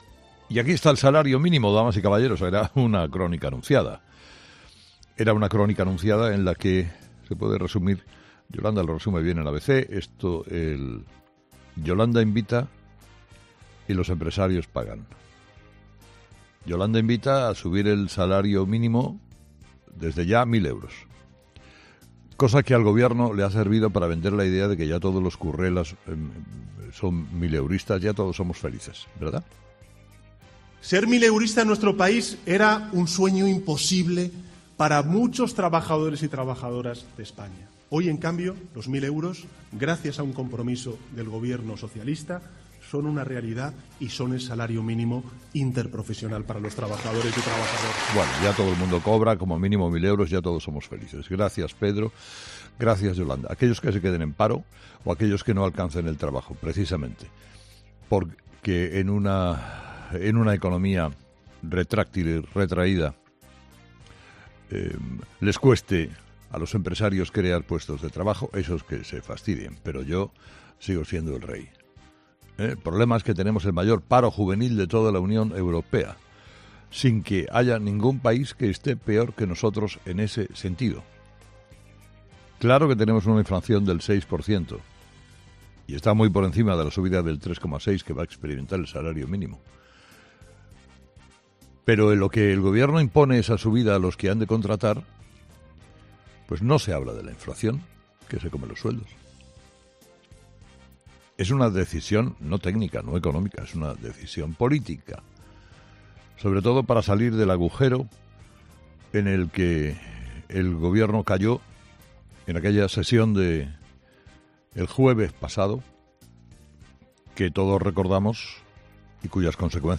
Carlos Herrera, director y presentador de 'Herrera en COPE', ha comenzado el programa de este jueves analizando las principales claves de la jornada, que pasan, entre otros asuntos, por la retirada de las mascarillas en exteriores y la subida del SMI aprobada por el Gobierno.